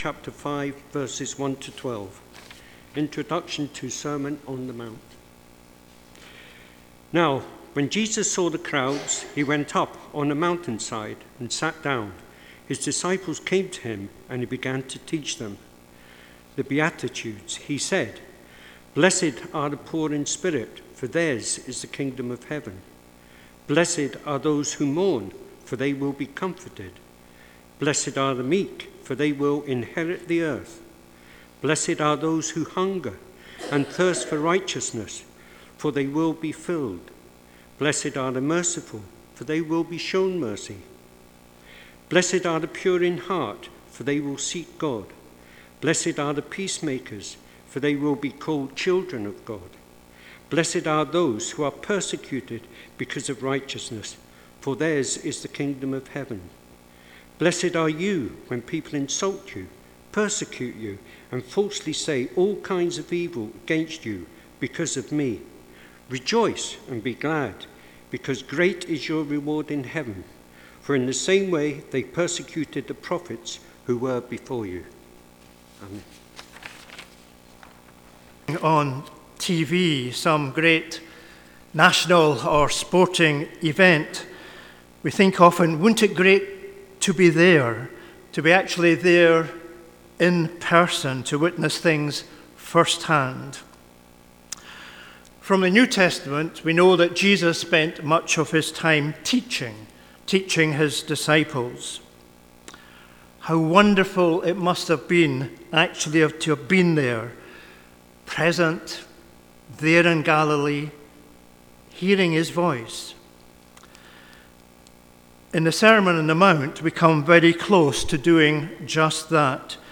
Matthew 5:1-12 Service Type: Sunday Morning Topics: The Beatitudes